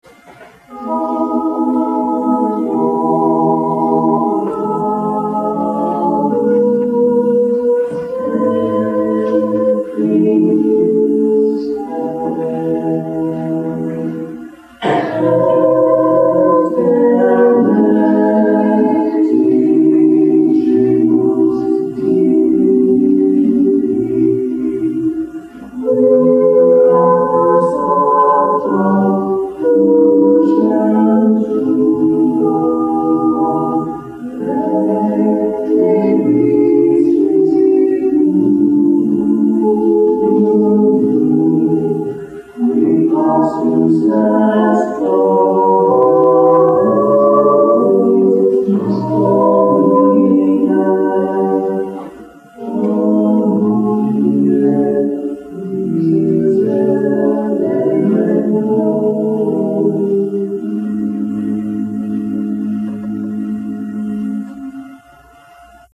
We did a concert at the end of the 6? weeks, and our professor had me sing in a group of 4 for one song. I can’t even remember or tell if I’m singing tenor or bass for sure, but I think it was bass. I was very nervous, and I think the rest of my group was too.